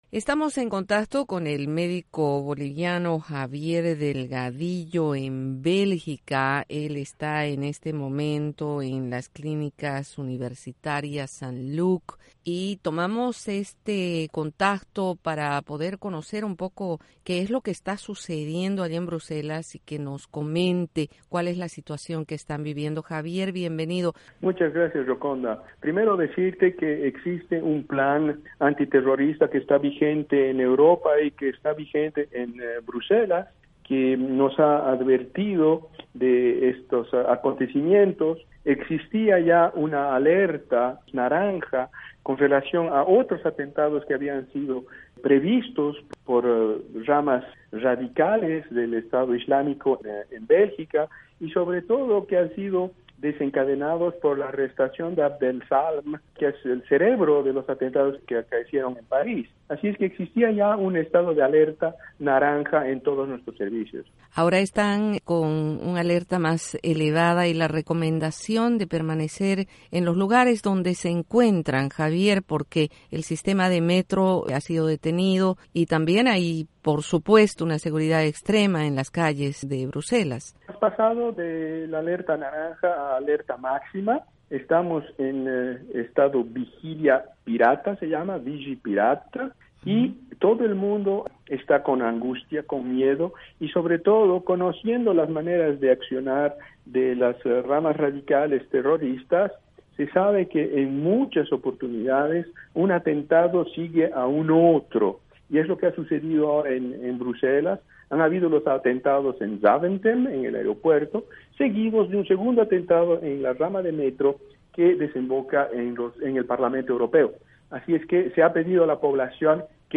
Insertar share Entrevista con el médico boliviano